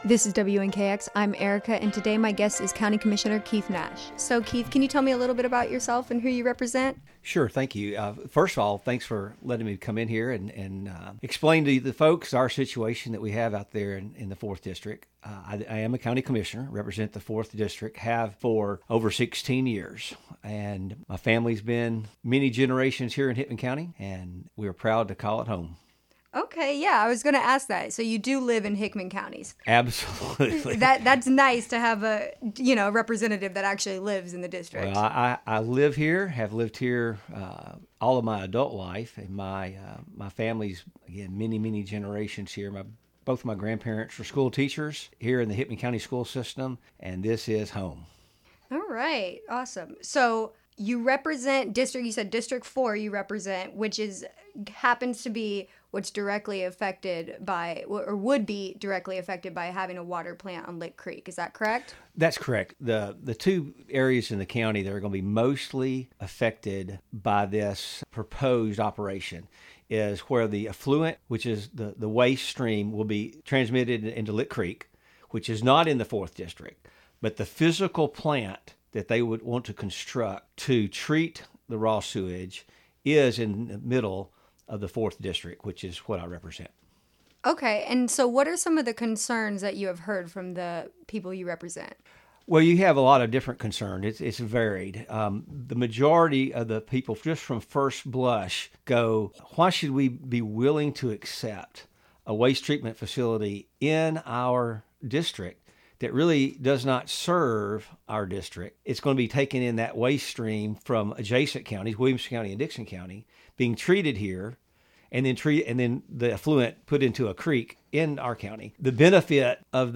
Editted-Interview-2-Lick-Creek-1.mp3